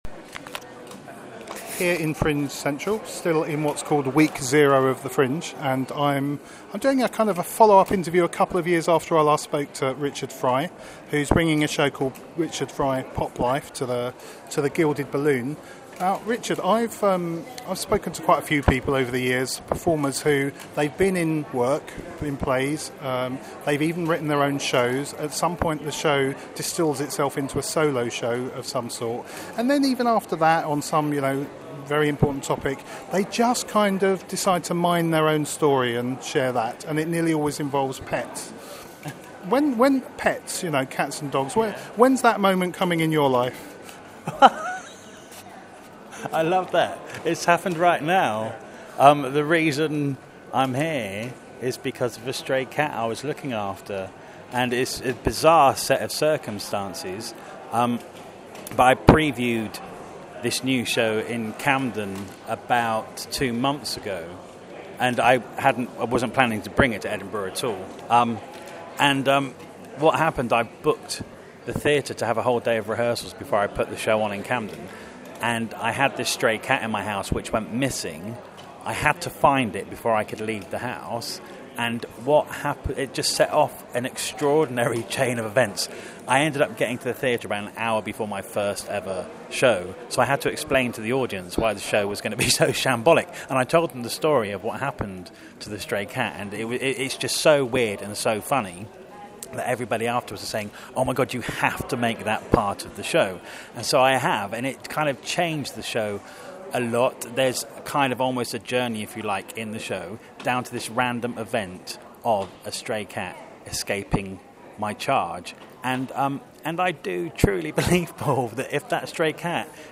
Edinburgh Audio 2014